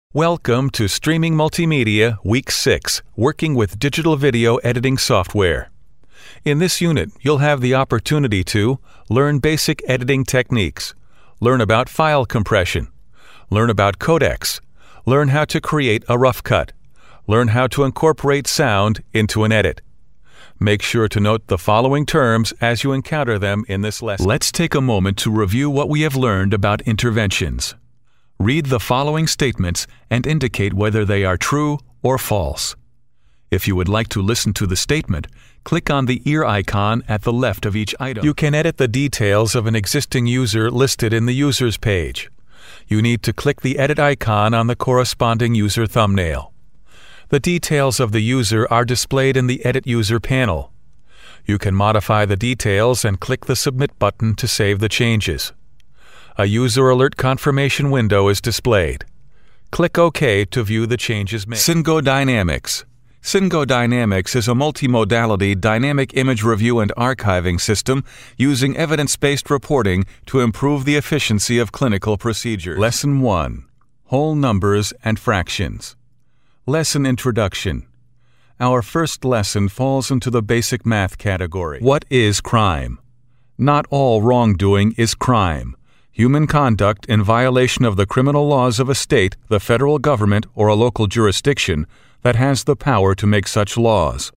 My studio is fully equipped, including ISDN and phone patch for remote recording and direction.
Voice overs, US English, narrations, ISDN, promo, imaging, mid, young, middle, dark, brightly, middle west, mid-Atlantic
Sprechprobe: eLearning (Muttersprache):